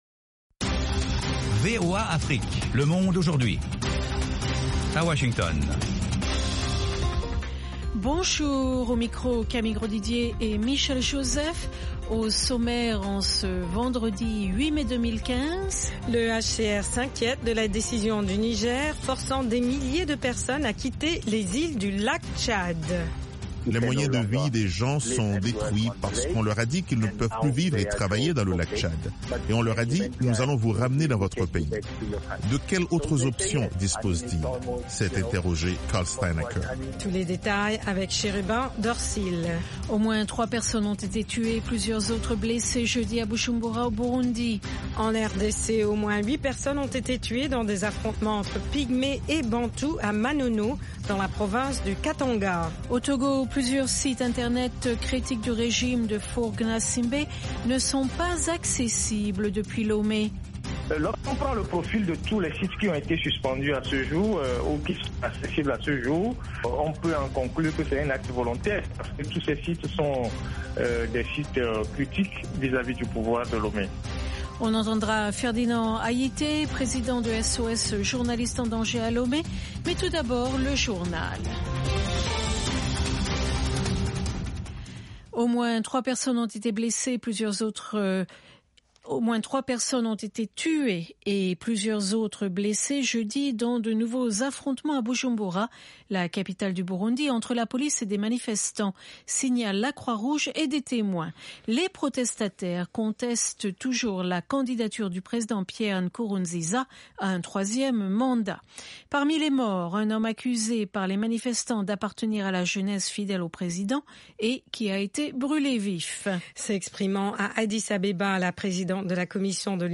sélection spéciale de musique malienne et internationale